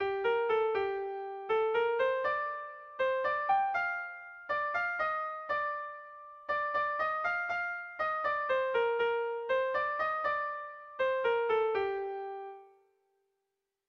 Erromantzea
Kopla handia